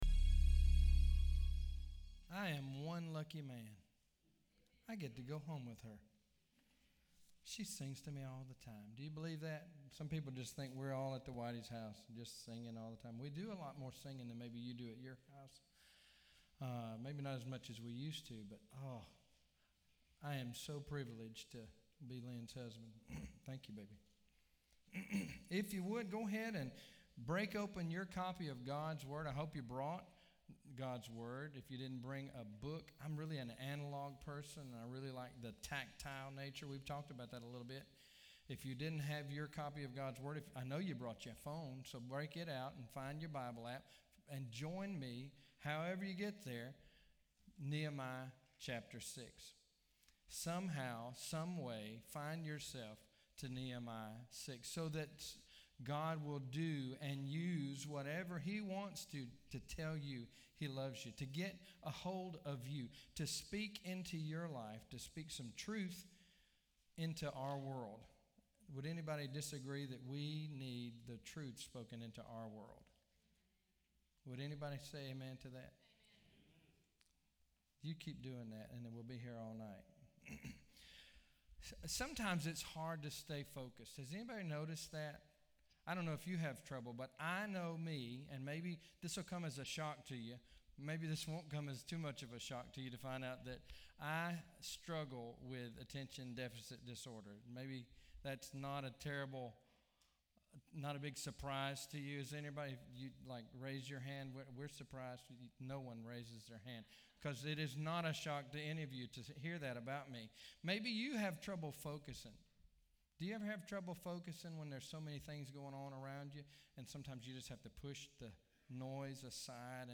FIRST BAPTIST CHURCH OPP Sermons 2020